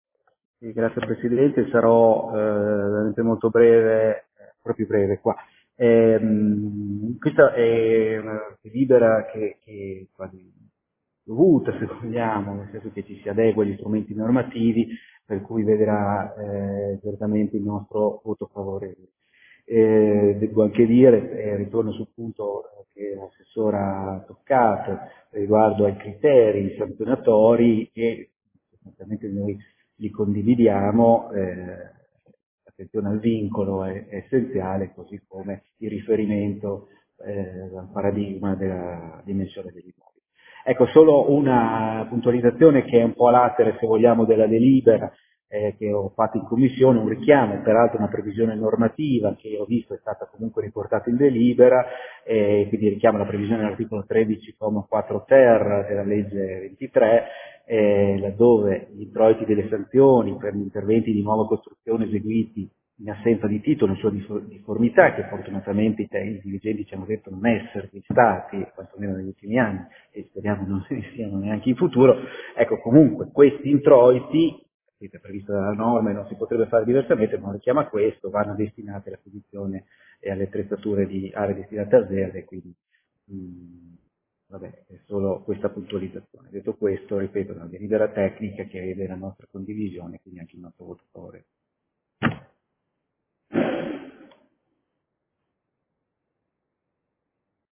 Giovanni Silingardi — Sito Audio Consiglio Comunale